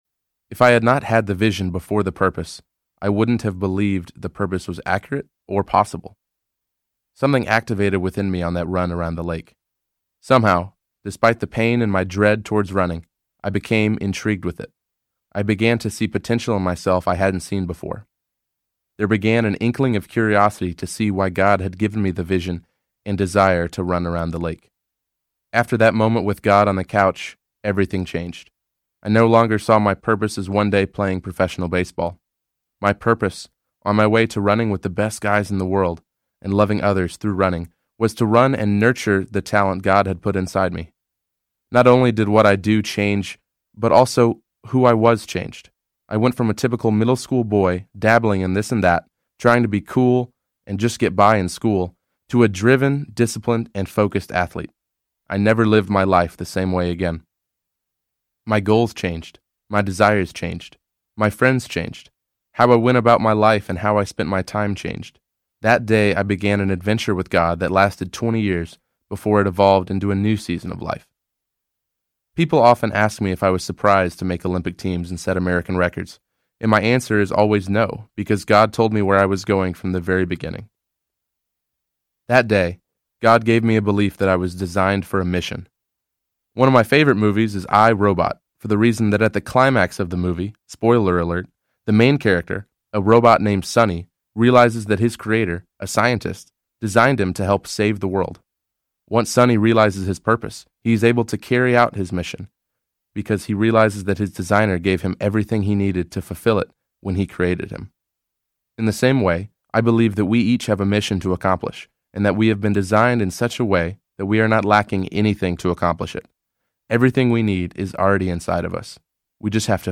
Run the Mile You’re In Audiobook
Narrator